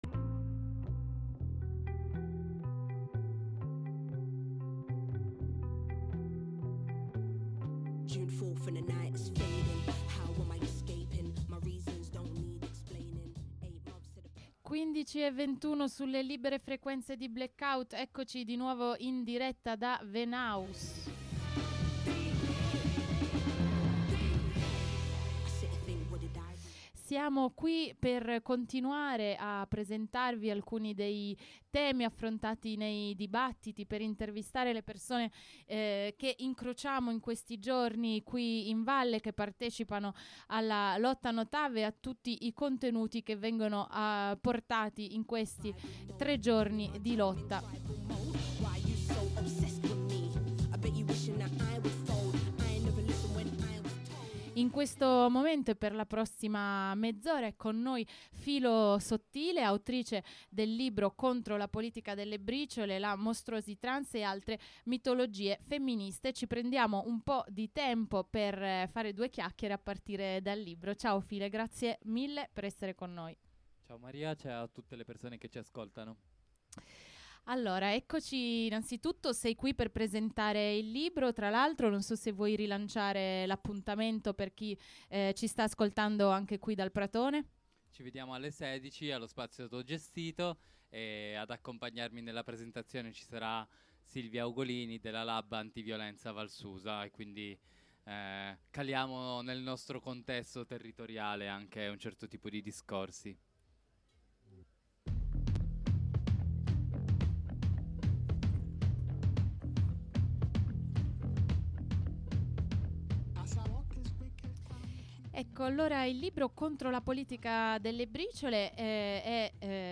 RBO al Festival Alta felicità